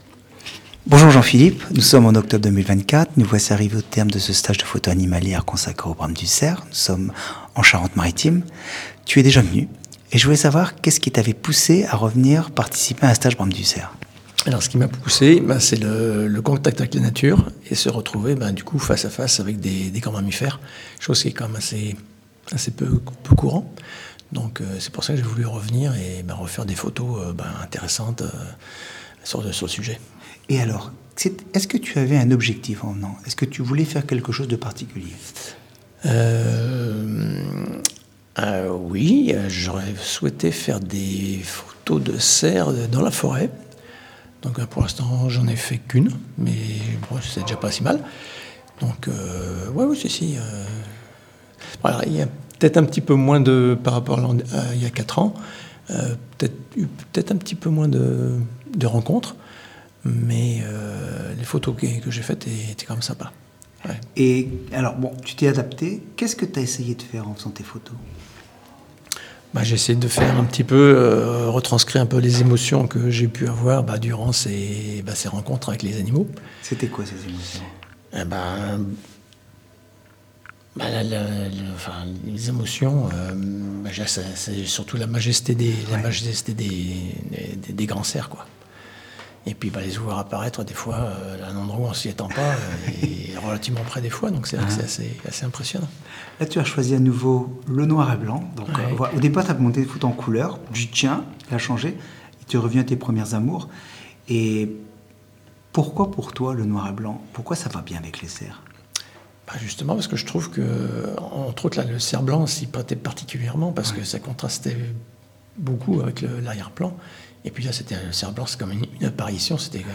Le commentaire oral des participants